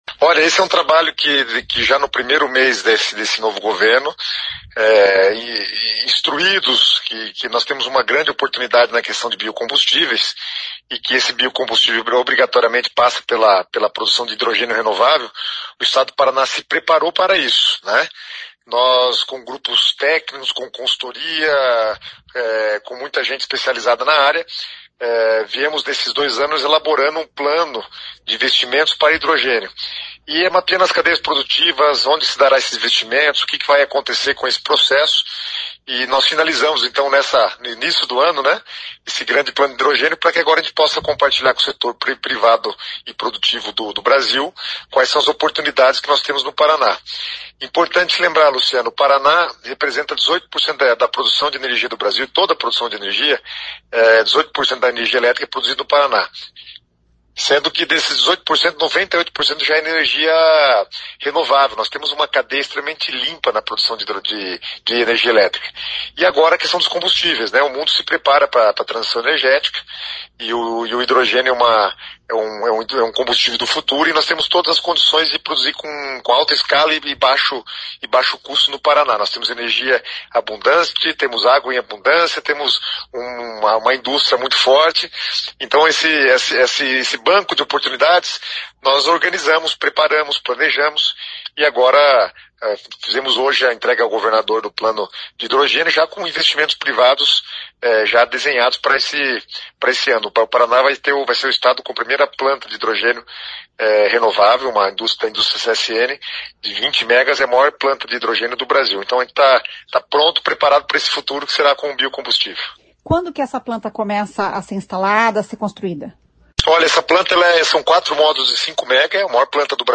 Em entrevista, Guto Silva fala sobre o assunto e sobre a construção de um gasoduto na região norte do estado.